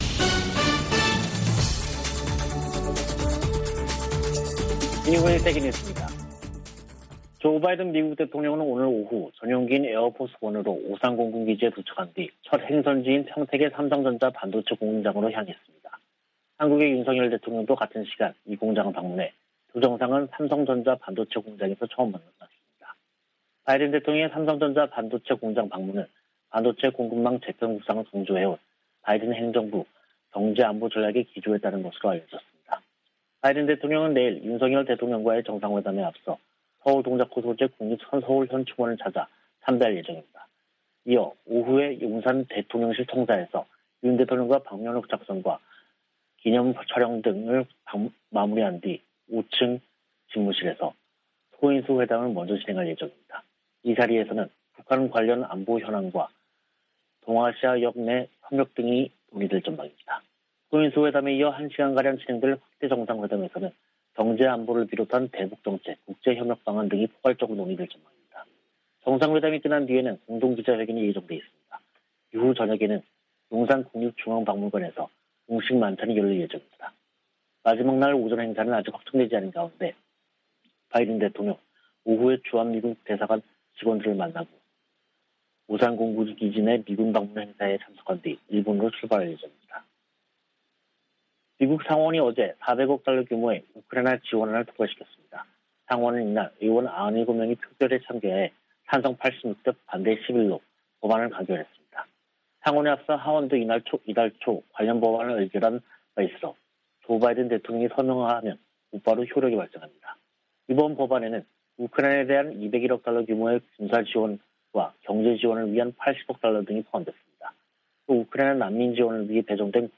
VOA 한국어 간판 뉴스 프로그램 '뉴스 투데이', 2022년 5월 20일 3부 방송입니다. 조 바이든 미국 대통령이 방한 첫 일정으로 평택 삼성전자 반도체 공장을 방문해 미한 간 기술동맹을 강조했습니다. 경제안보 현안과 대응전략을 논의하는 백악관과 한국 대통령실 간 대화채널을 구축하기로 했습니다. 미 국방부는 북한의 도발에 대응해 민첩한 대비태세를 취하고 있다고 밝혔습니다.